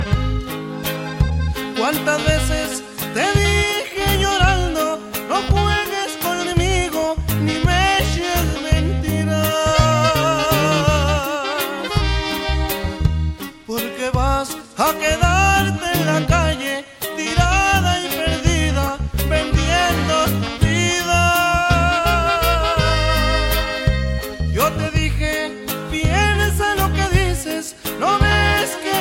Descarga de Sonidos mp3 Gratis: columpio.
columpio-ringtones.mp3